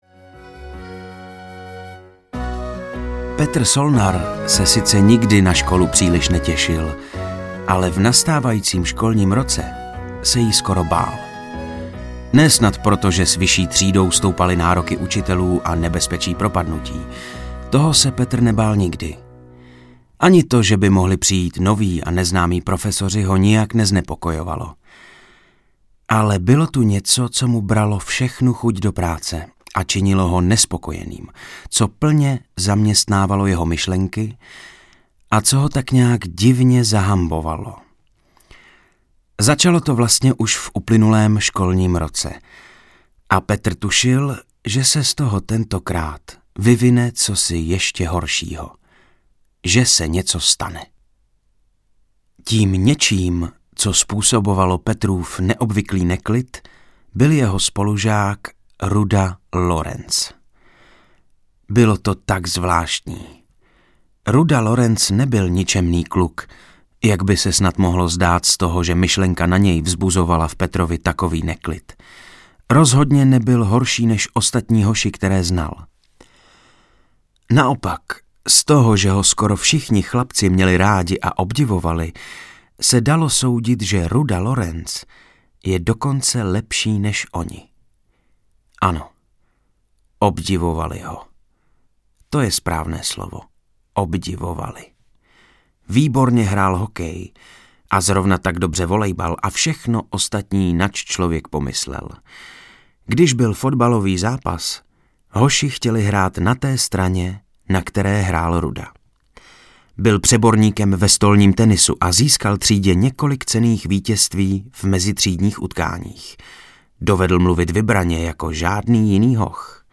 Boj o první místo audiokniha
Ukázka z knihy
• InterpretVojtěch Kotek